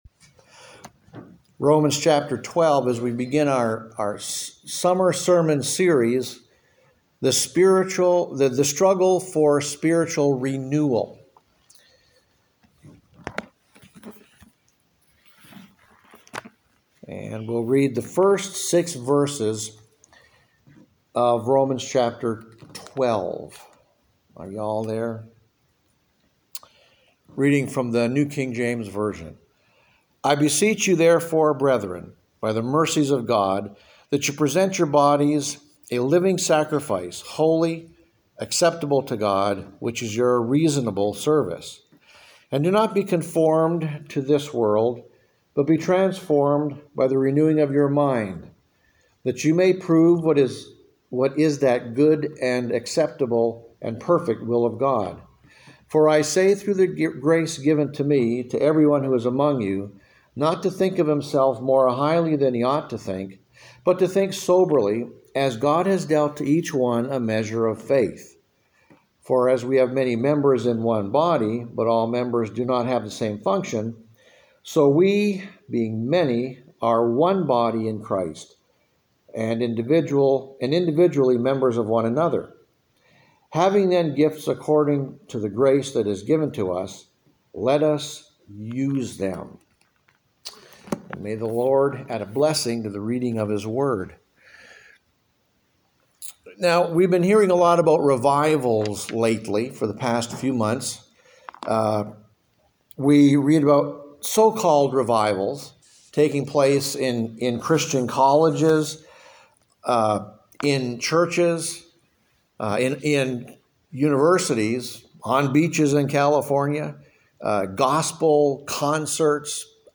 The Sunday Sermon – Renew My Church!